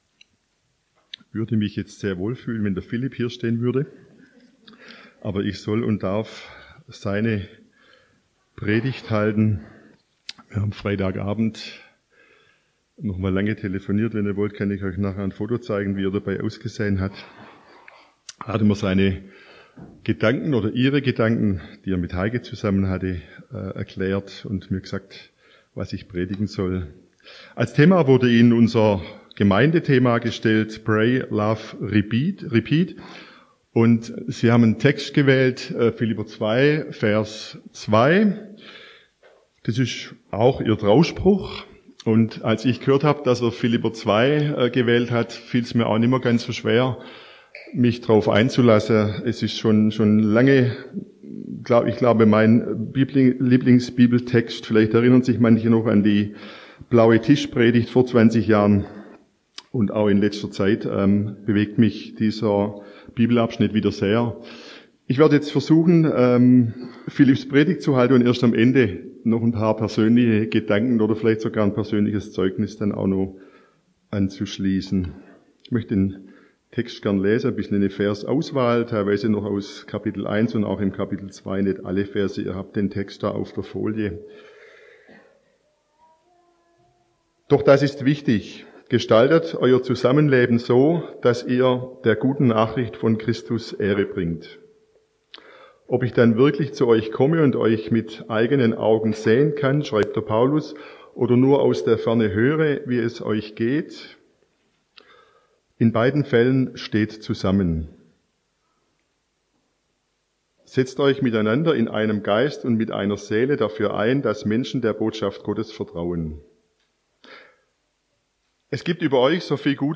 Repeat ~ Predigten aus der Fuggi Podcast